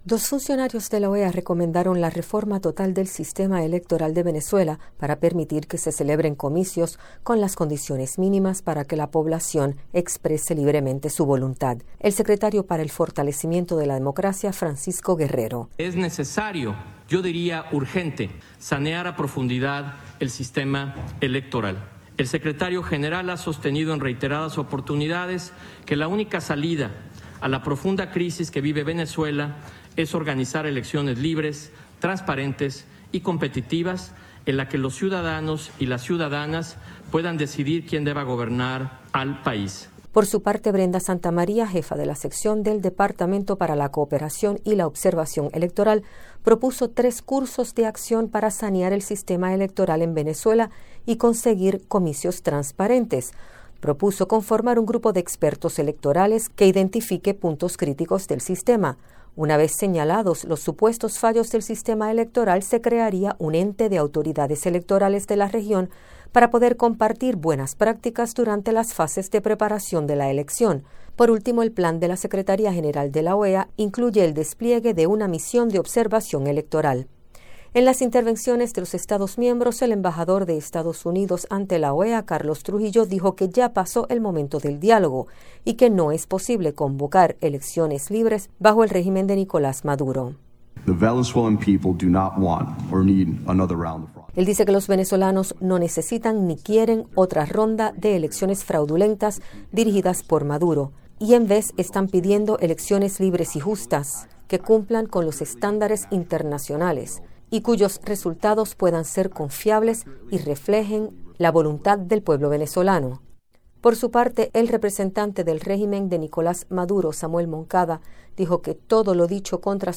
Noticias de Radio Martí